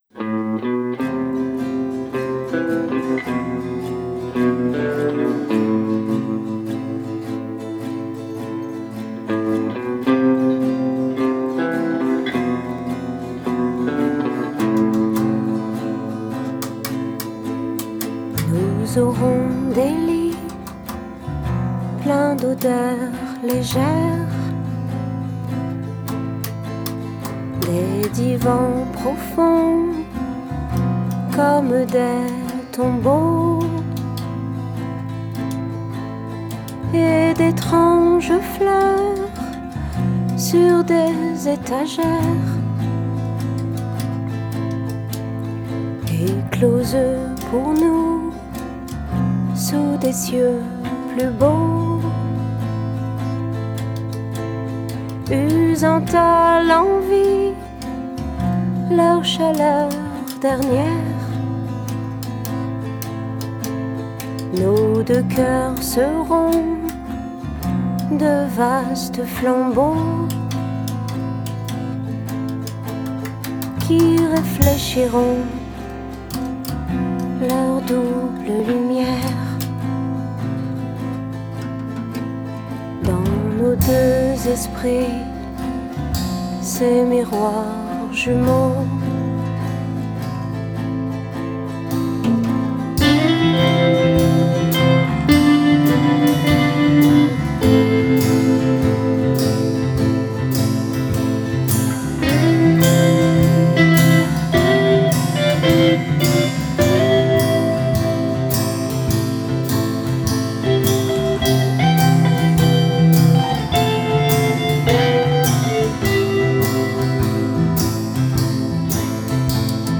guitares/chant
batterie
basse
Enregistré rue de la Fidélité, Paris 10,